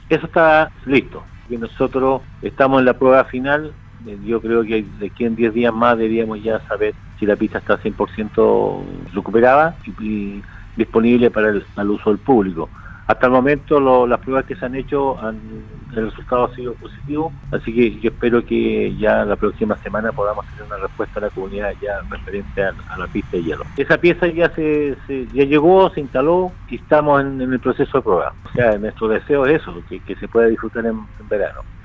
En conversación con Radio Sago, el alcalde de Osorno, Emeterio Carrillo, entregó novedades respecto a las principales pistas que están entrampadas para ser utilizadas por la ciudadanía.